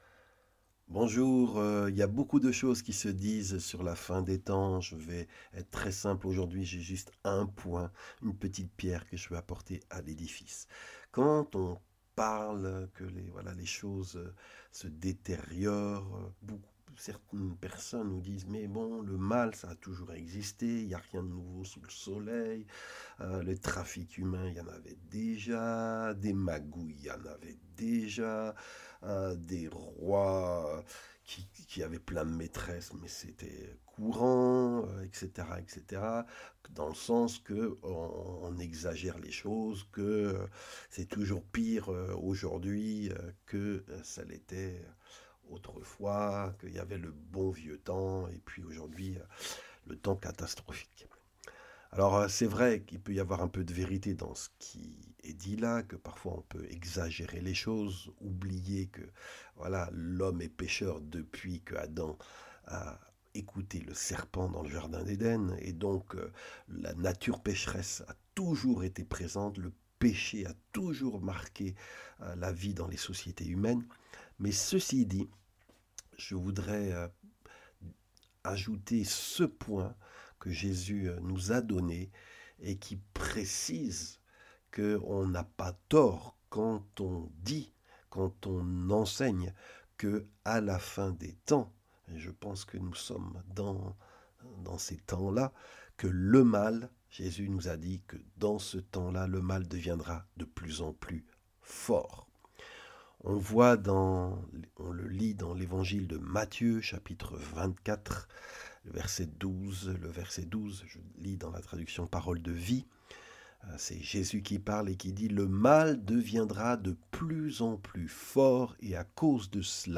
Enseignement biblique sur la fin des temps : Selon Jésus un signe de la fin des temps ce sera l'accroissement du mal, de l'apostasie et la diminution de l'amour.